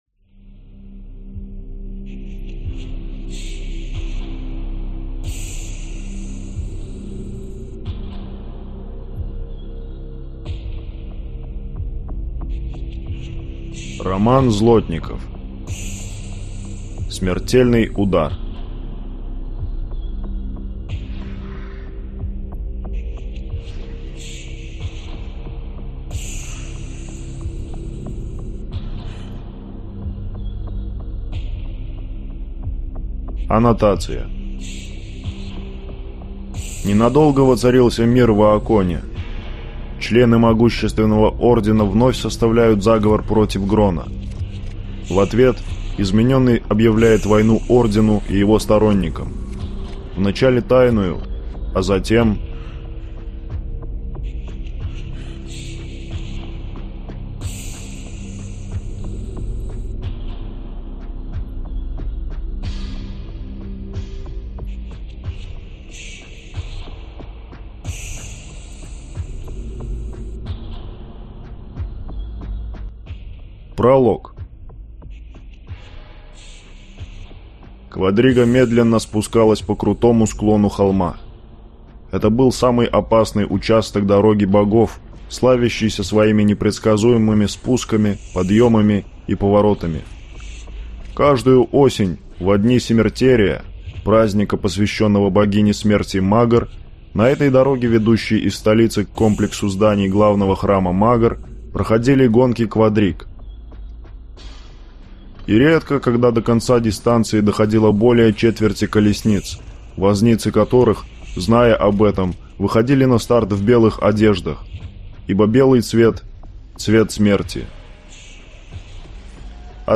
Аудиокнига Смертельный удар | Библиотека аудиокниг